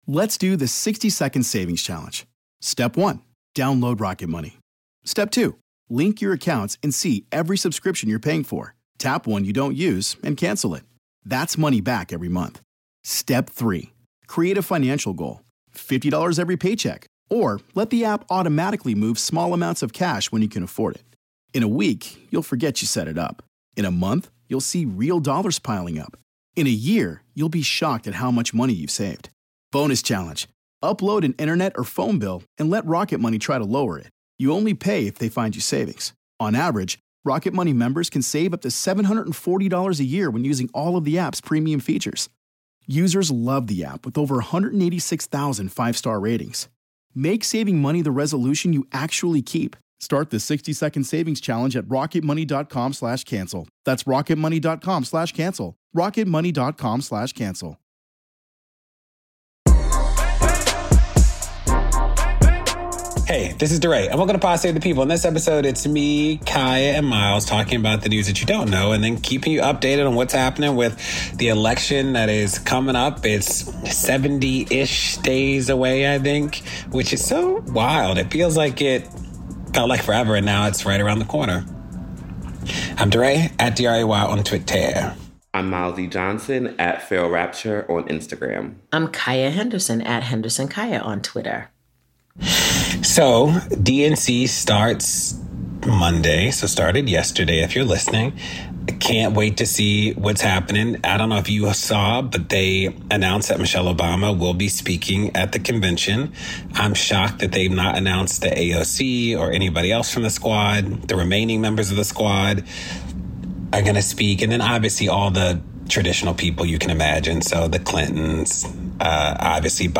Hosts debate the prominence of celebrity culture in politics, a historic payout to Black farmers, and wealthy Arizonians blow threw the state budget.